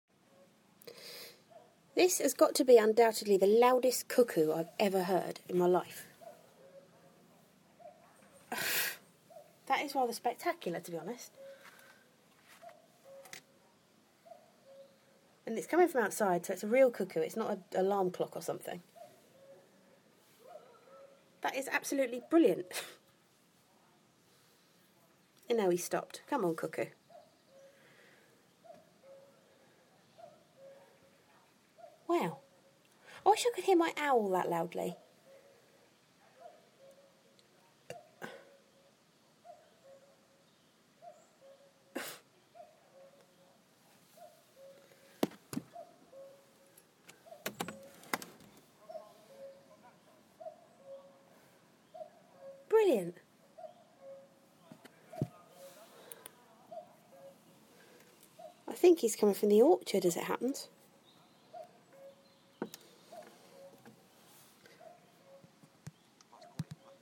1 loud cuckoo!
As recorded from my bedroom window sill.